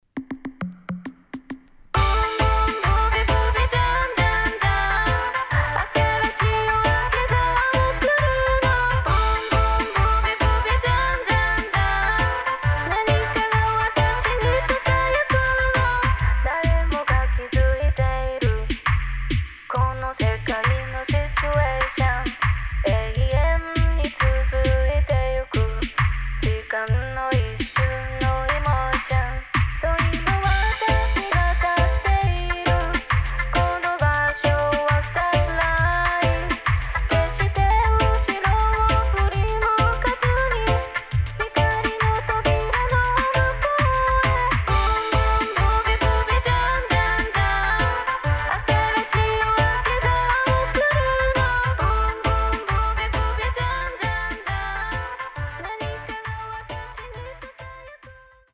ジャパニーズ・レゲエ、ルーツ・ダブの新境地